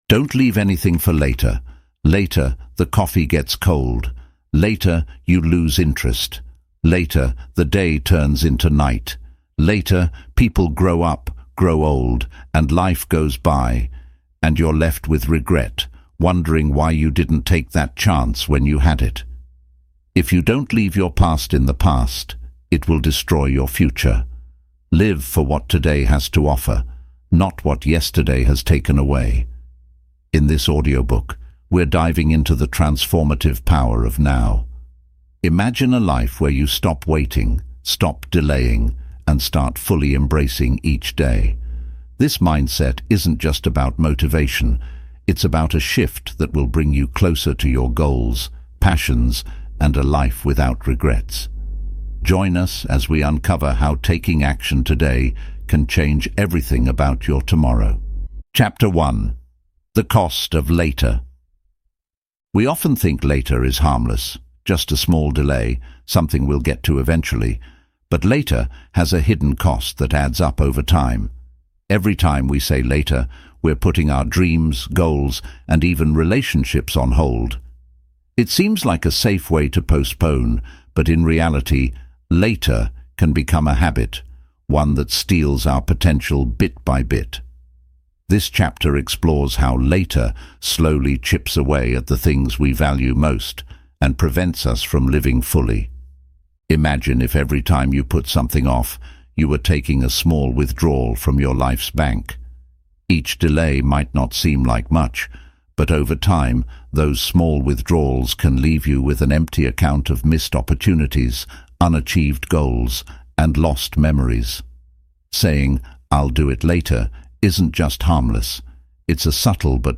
The Power of NOW: Stop Procrastinating and Take Control | Audiobook